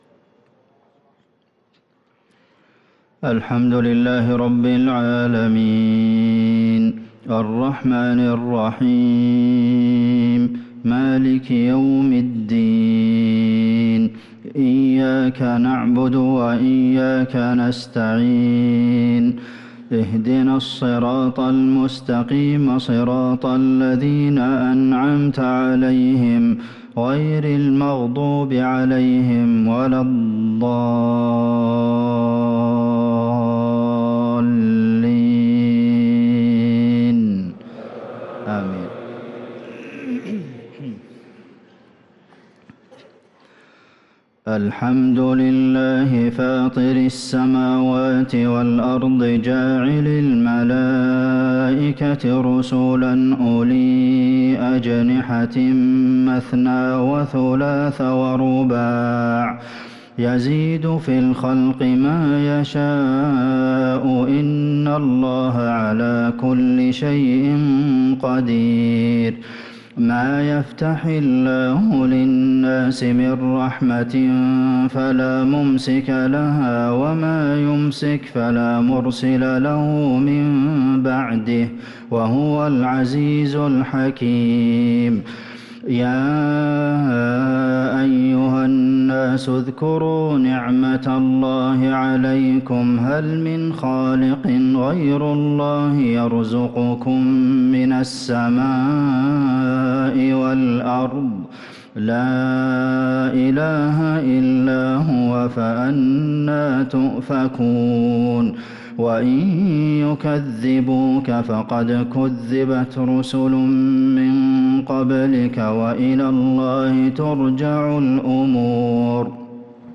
صلاة العشاء للقارئ عبدالمحسن القاسم 20 ربيع الآخر 1445 هـ